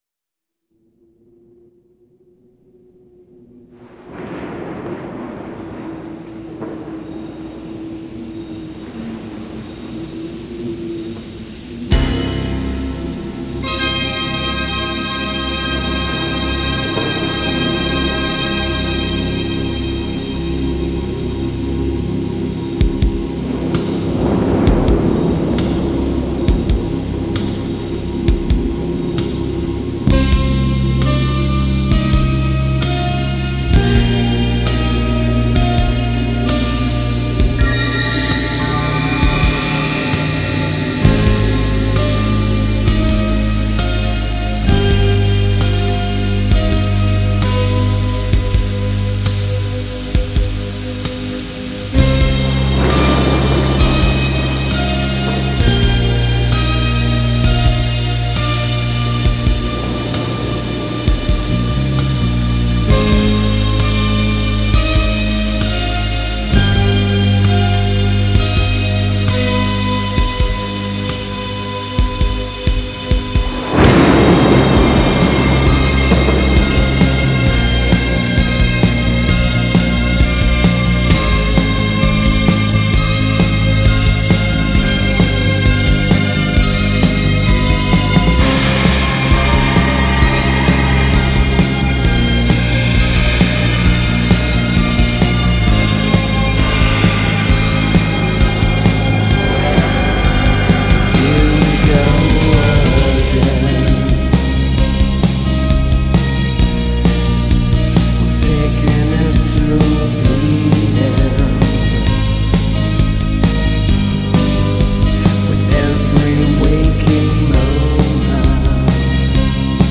gothic rock